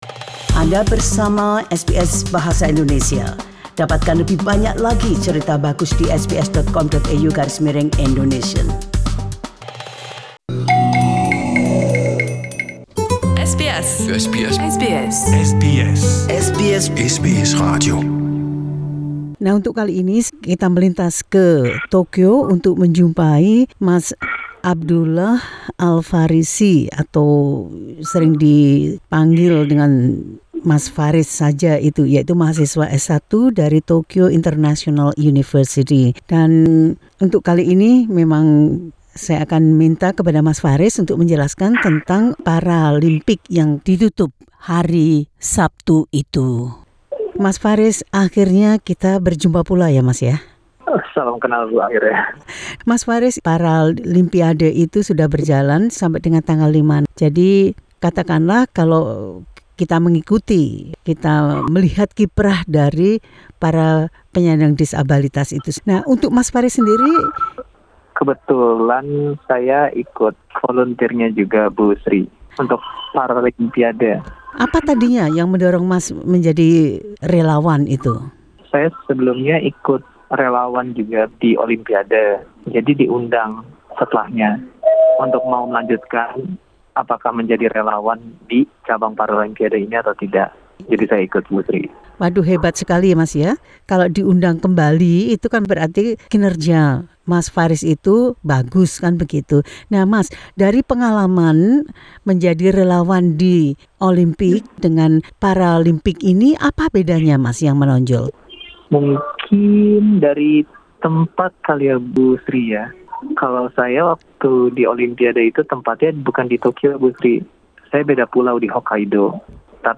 SBS Indonesian View Podcast Series Follow and Subscribe Apple Podcasts YouTube Spotify Download (38.49MB) Download the SBS Audio app Available on iOS and Android On 05 September the Tokyo 2020 Paralympics officially ended.